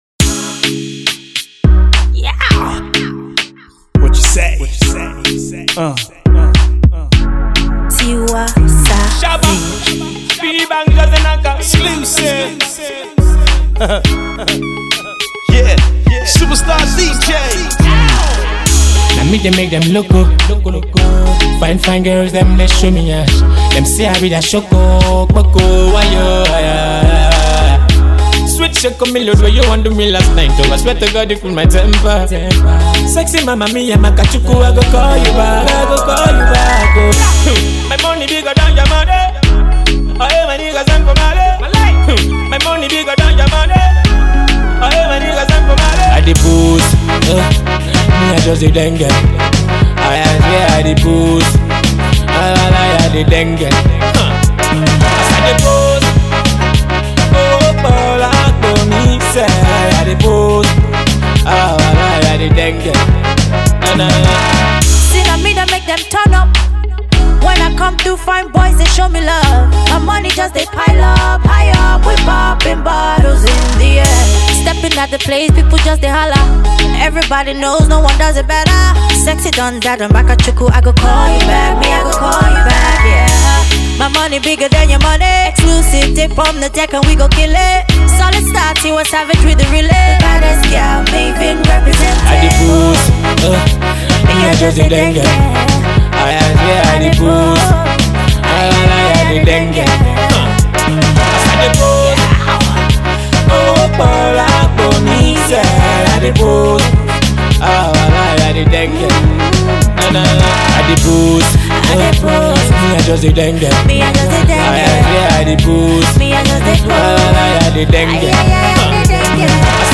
massive melodious tune